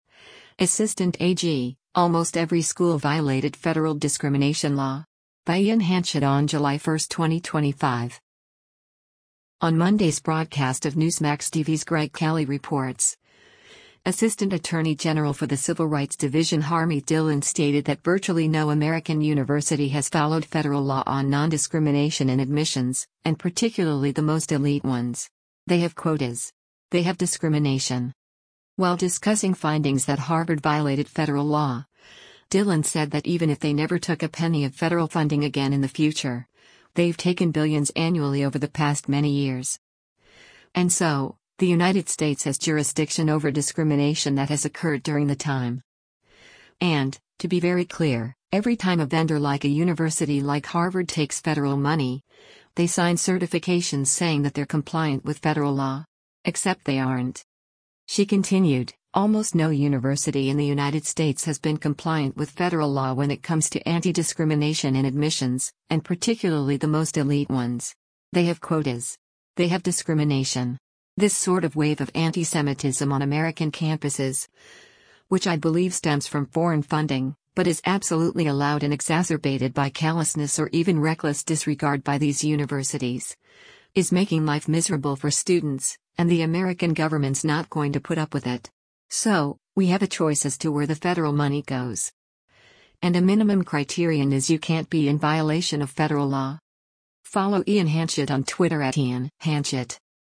On Monday’s broadcast of Newsmax TV’s “Greg Kelly Reports,” Assistant Attorney General for the Civil Rights Division Harmeet Dhillon stated that virtually no American university has followed federal law on non-discrimination in admissions, “and particularly the most elite ones. They have quotas. They have discrimination.”